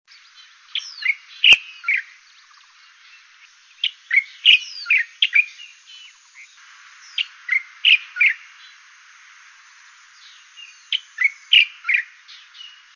10-1金山白頭翁1.mp3
物種名稱 白頭翁(台灣亞種) Pycnonotus sinensis formosae
錄音地點 新北市 金山區 金山
錄音環境 路旁灌木叢
行為描述 單隻鳴叫